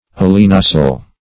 Meaning of alinasal. alinasal synonyms, pronunciation, spelling and more from Free Dictionary.
alinasal.mp3